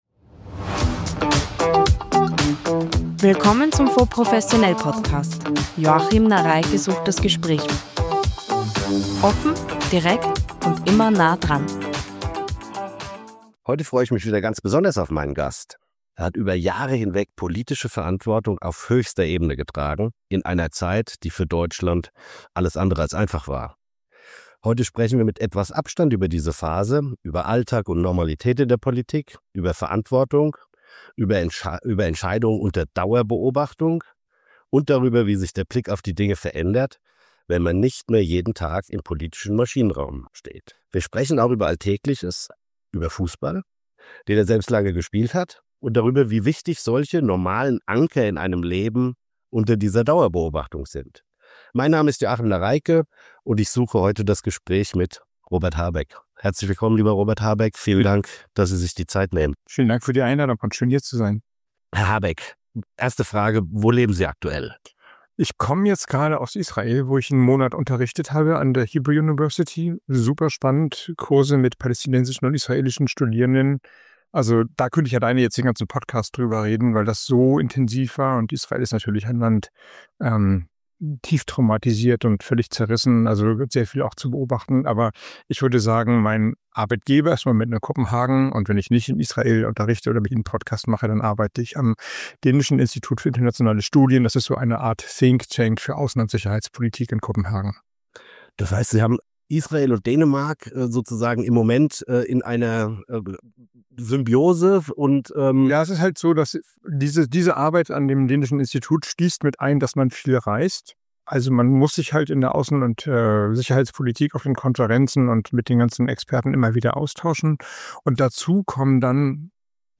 Der frühere Vizekanzler und Wirtschaftsminister Robert Habeck spricht im FONDS professionell Podcast über sein Leben nach der Politik, Entscheidungen unter Druck, die Krise der Demokratie – und darüber, warum er sich derzeit "jeden Tag jünger" fühlt.